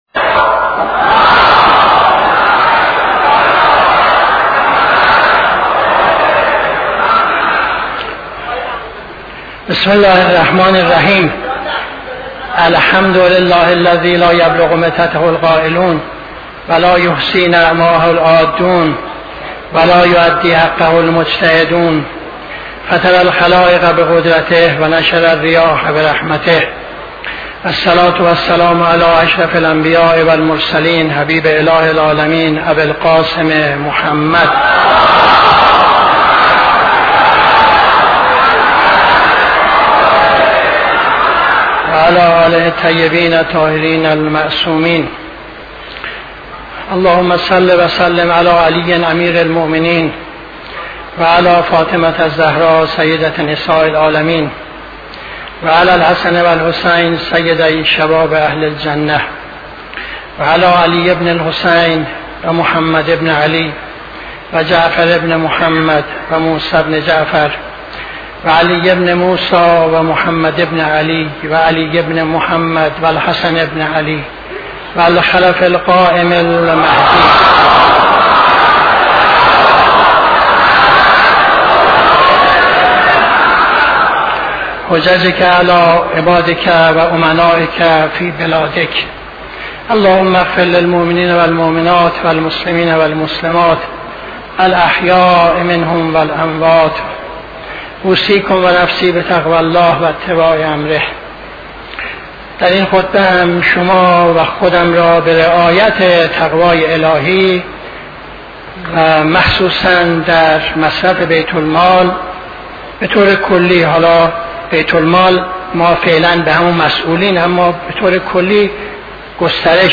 خطبه دوم نماز جمعه 00-00-75